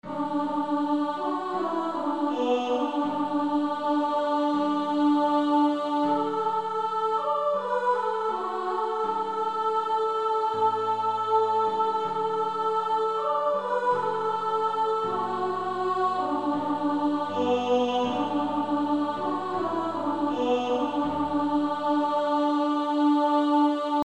(SATB) Author: African-American Spiritual
Practice then with the Chord quietly in the background.